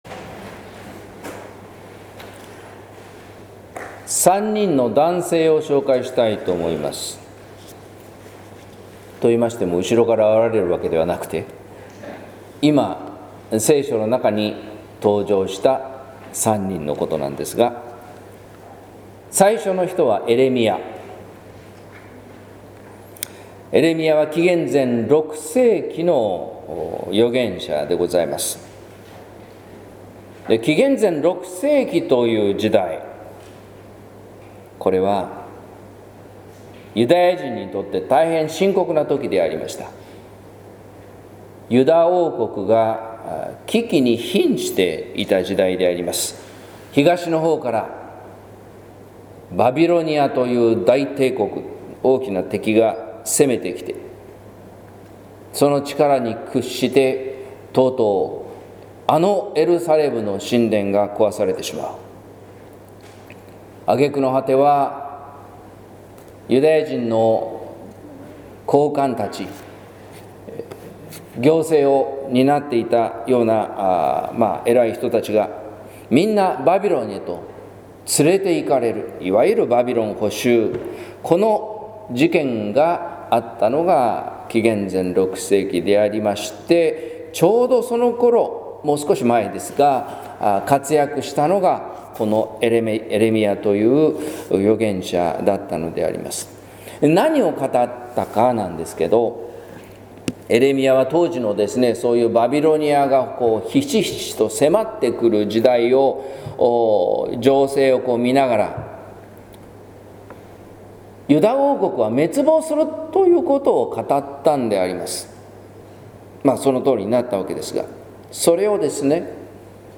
説教「矛盾なき十字架」（音声版）